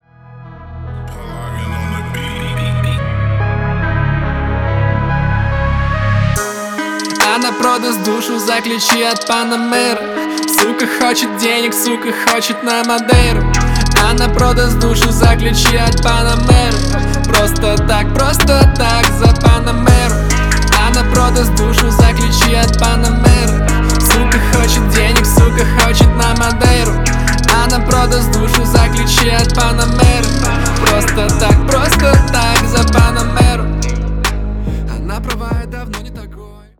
• Качество: 320, Stereo
громкие
Хип-хоп
русский рэп
мощные басы